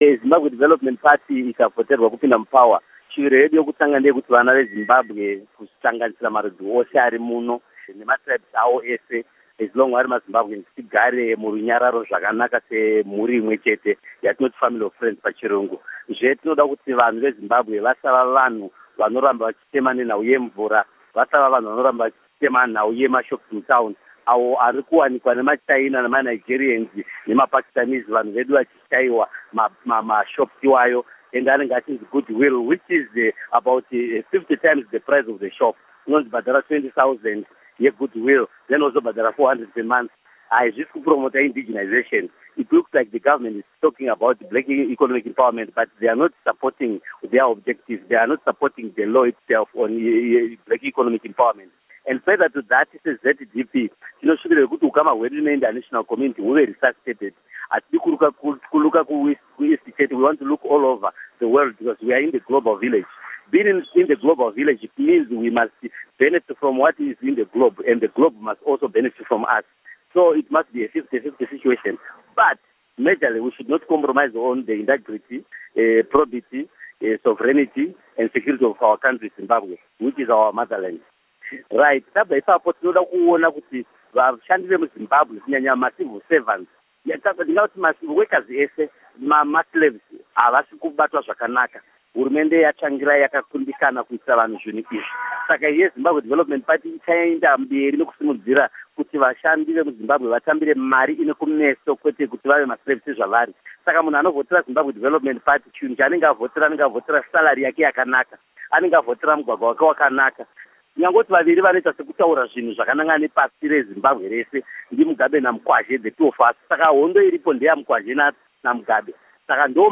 Huukuro